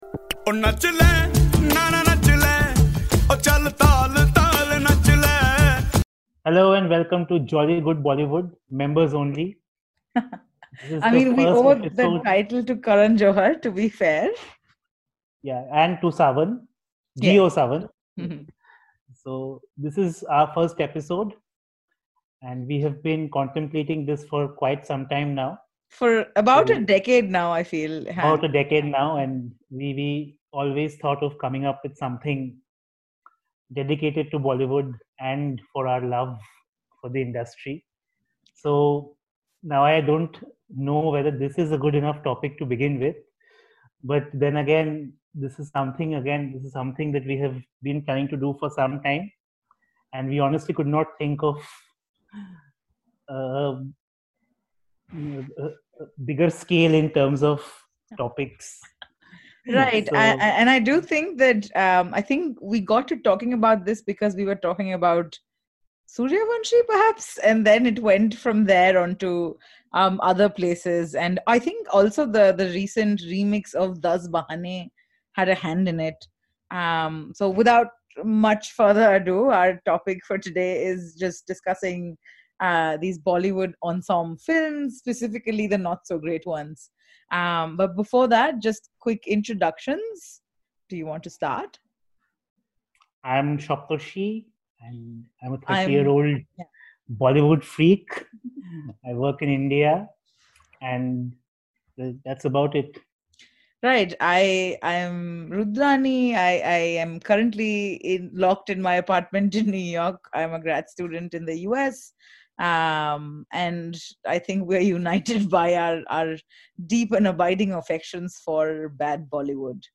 And because we recorded it over a Zoom call, there's a lot of talking over one another.
We do not own the music played before and after the film.